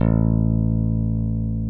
P-B PICK A2.wav